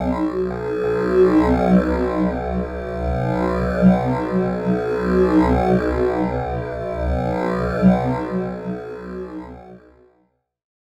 Index of /90_sSampleCDs/Club_Techno/Atmos
Atmos_05_B1.wav